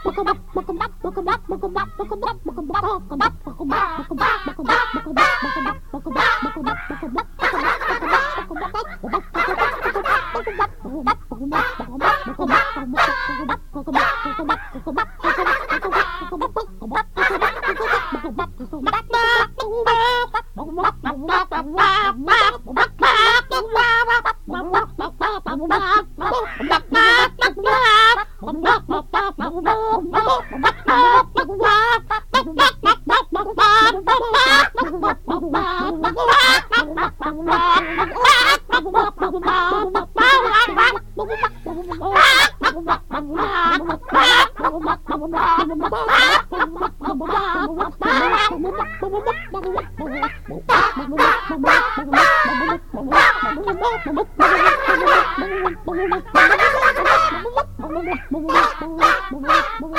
ROCK / 80'S/NEW WAVE. / 80'S / NOVELTY / STRANGE